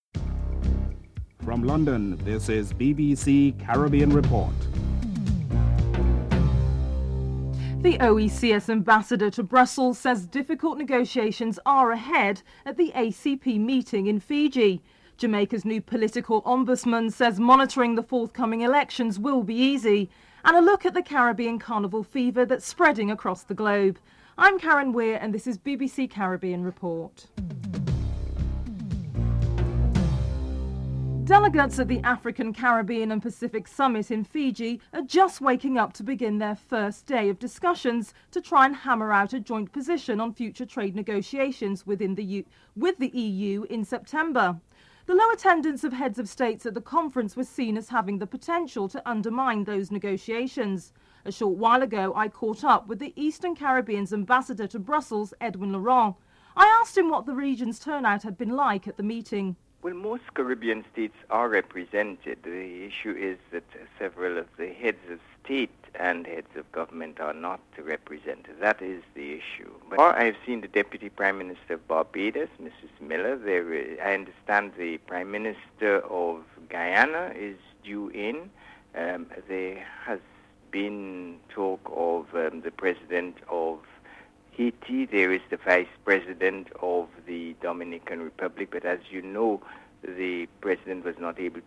1. Headlines (00:00-00:27)